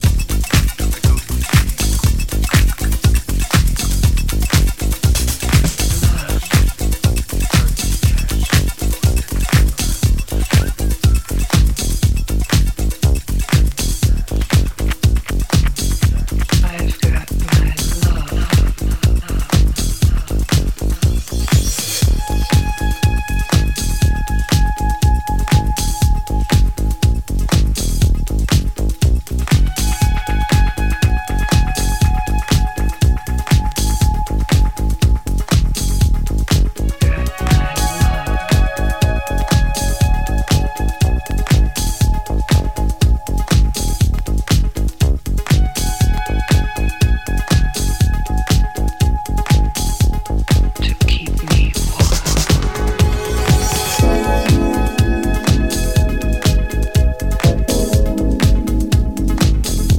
известнейший house исполнитель